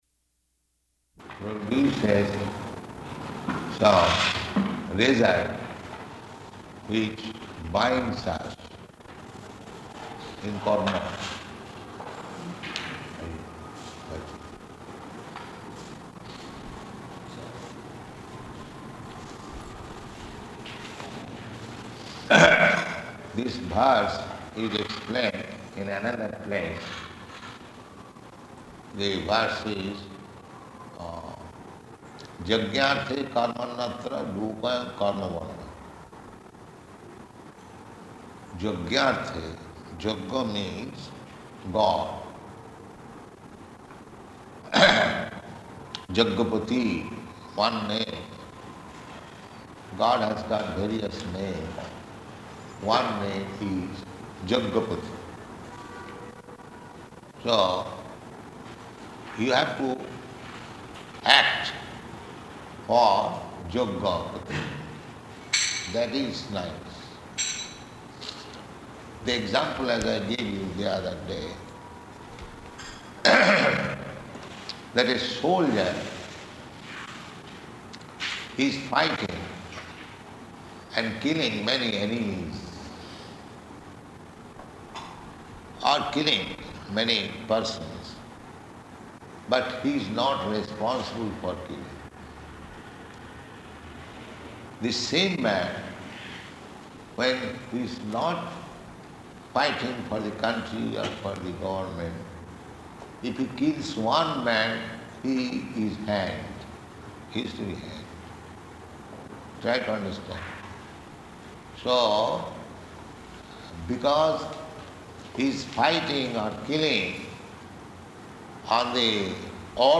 Bhagavad-gītā 3.9 --:-- --:-- Type: Bhagavad-gita Dated: November 3rd 1973 Location: Delhi Audio file: 731103BG.DEL.mp3 Prabhupāda: ...which produces some result which binds us in karma.